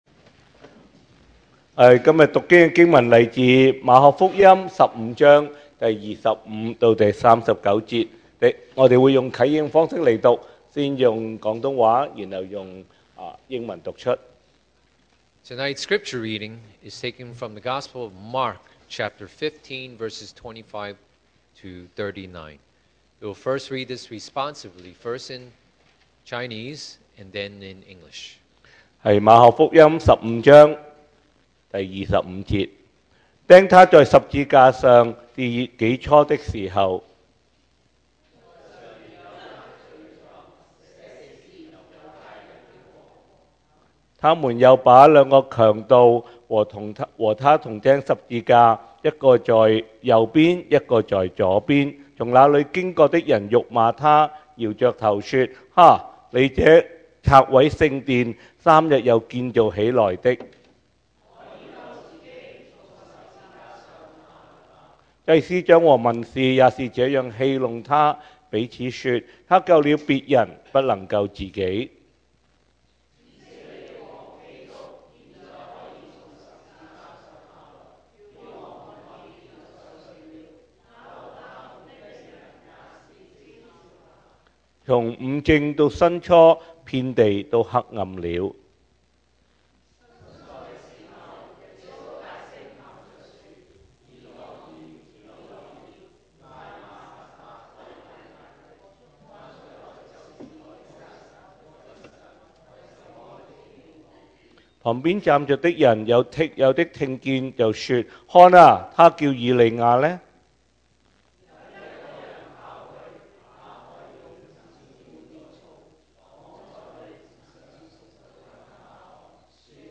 Service Type: Good Friday